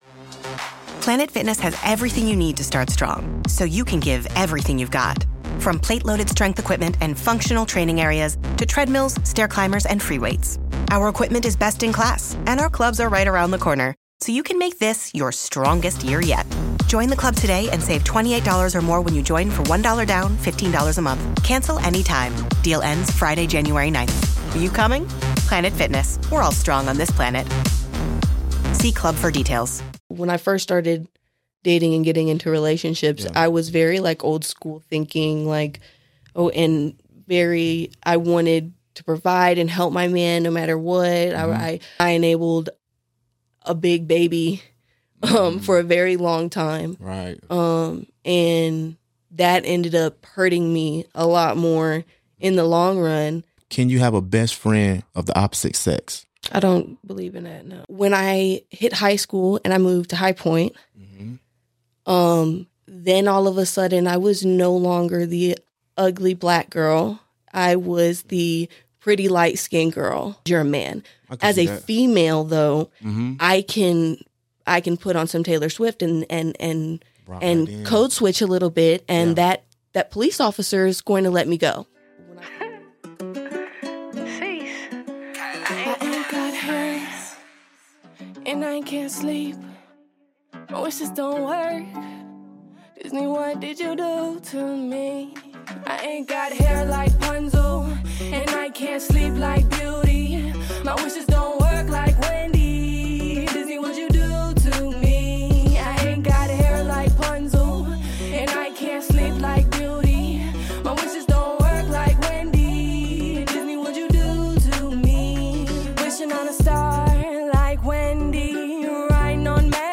This is a conversation about the resilience of the human spirit and the hidden strengths found in our most difficult seasons.